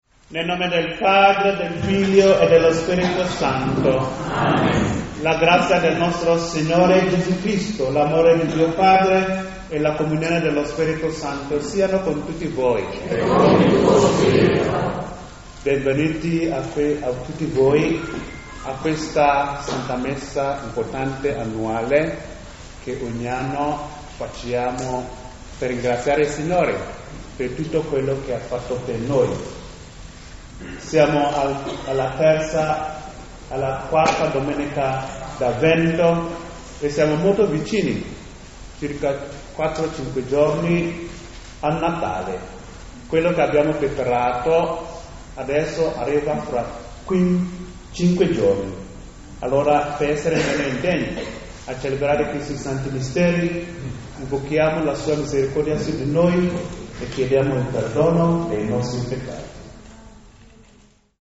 La celebrazione come ogni anno si è svolta nella palestra al piano terra dell’IMFR Gervasutta
accompagnata dalla “Corale Gioconda”, composta da Ammalati di Parkinson di Udine e dintorni.
CANTO E PREGHIERE INIZIALI
Corale Gioconda